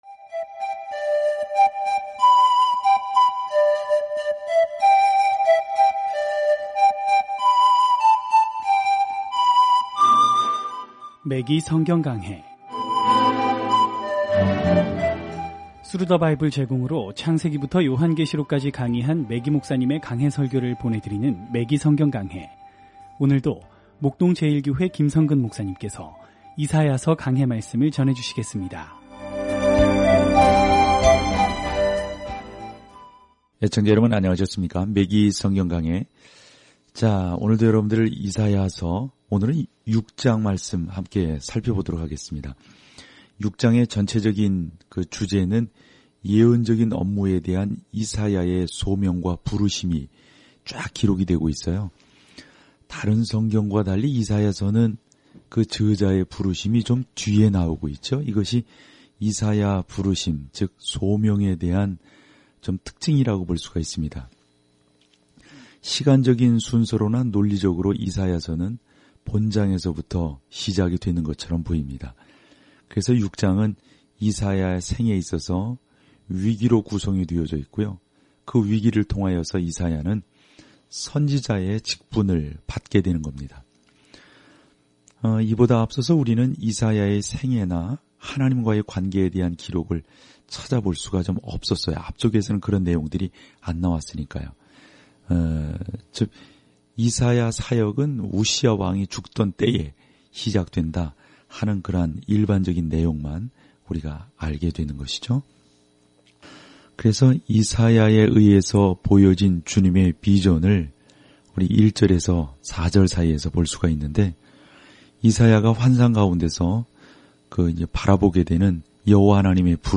말씀 이사야 6:1-5 6 묵상 계획 시작 8 묵상 소개 “다섯 번째 복음”으로 불리는 이사야는 정치적 적들이 유다를 점령할 암울한 때에 “많은 사람의 죄를 담당”할 왕과 종을 묘사하고 있습니다. 오디오 공부를 듣고 하나님의 말씀에서 선택한 구절을 읽으면서 매일 이사야서를 여행하세요.